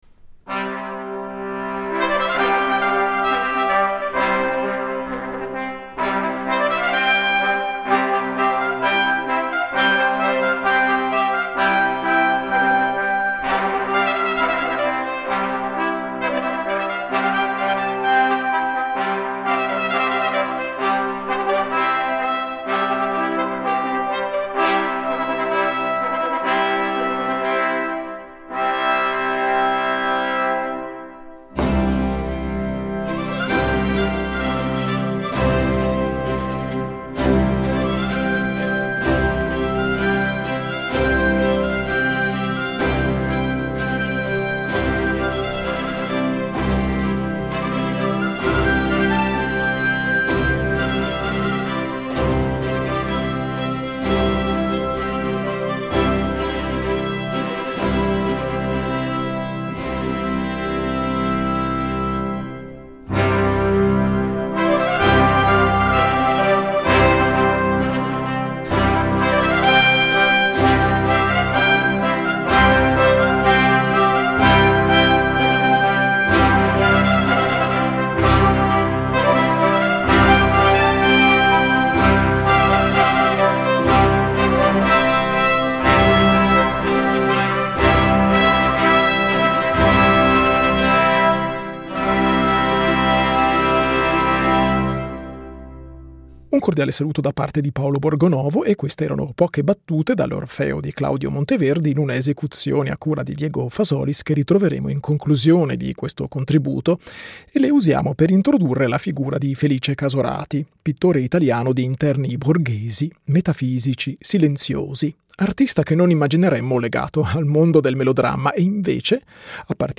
Vi riproponiamo oggi quella conversazione.